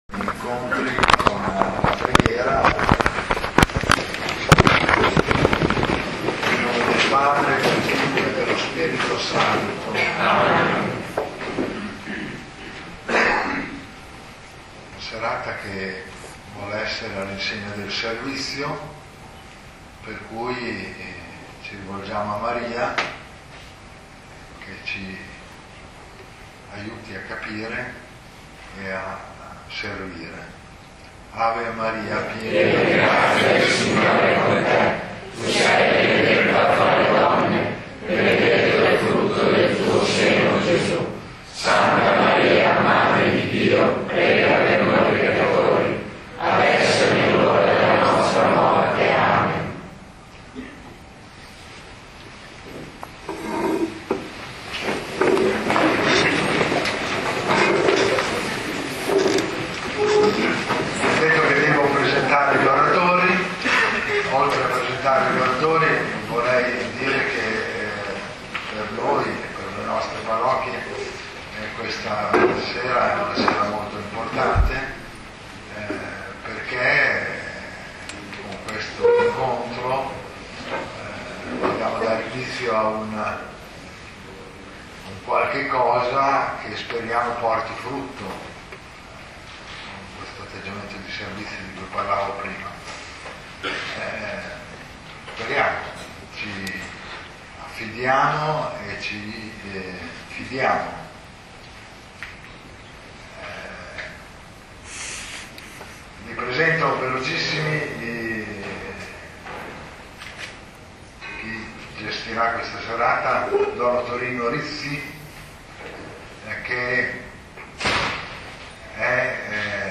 Unit� Pastorale di Castel Maggiore Luned� 1� marzo 2010 ore 21.00 Chiesa parrocchiale di San Bartolomeo primo incontro sulla dottrina sociale della Chiesa �Morte e vita, un prodigioso duello�